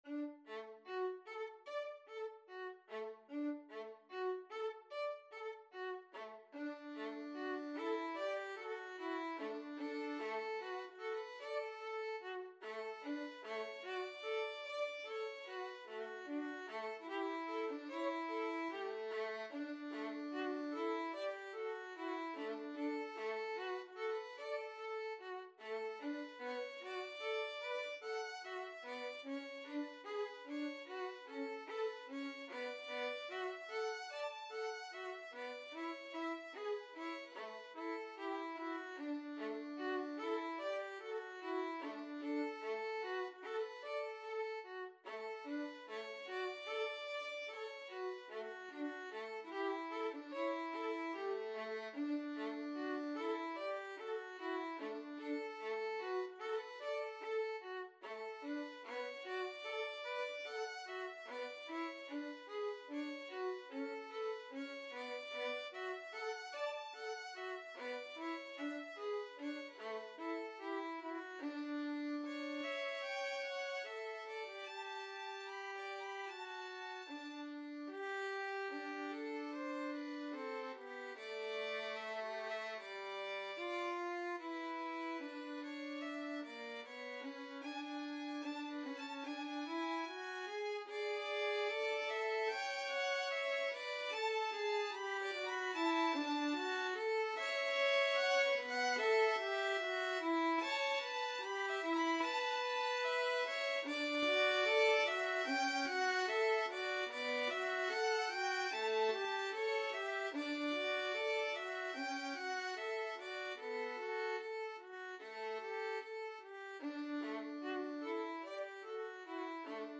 4/4 (View more 4/4 Music)
Classical (View more Classical Violin Duet Music)